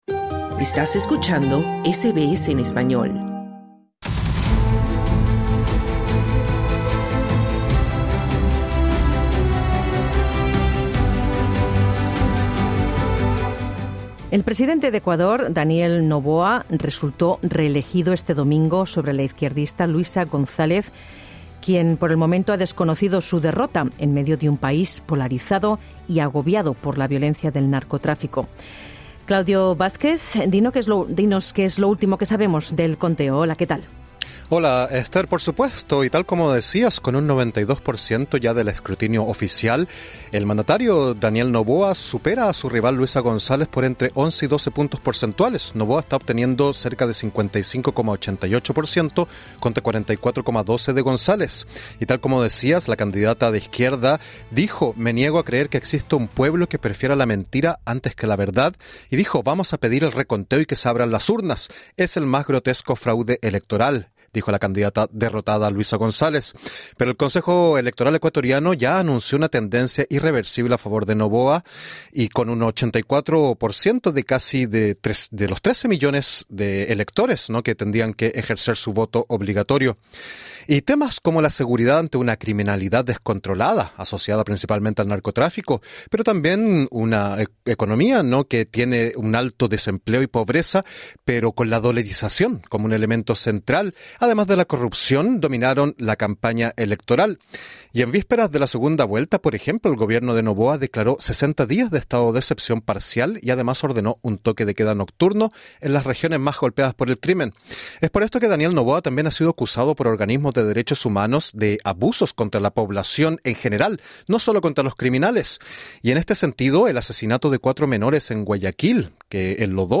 Con 92 por ciento del escrutinio oficial, el Consejo Nacional Electoral (CNE) reconoció una "tendencia irreversible" a favor del actual mandatario, Daniel Noboa. La candidata opositora correísta, Luisa González, desconoció los resultados electorales. Escucha la entrevista